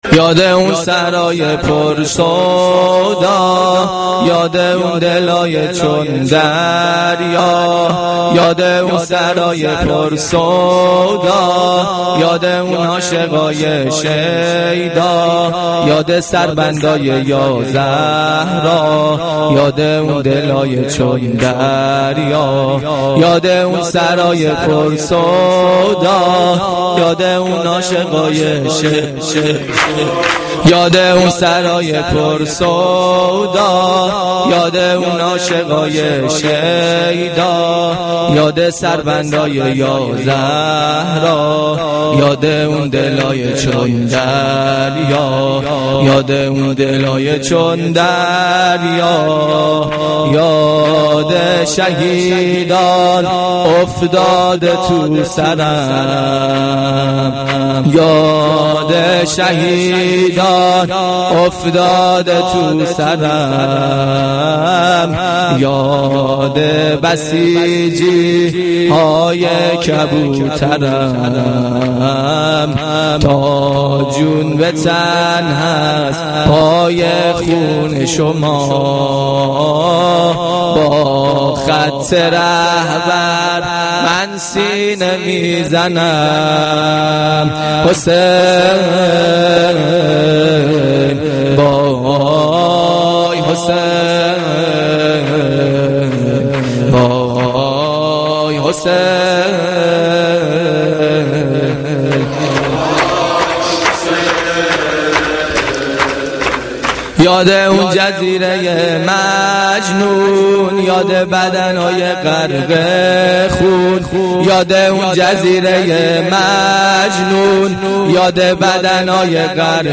مداحی
Shab-1-Moharam-5.mp3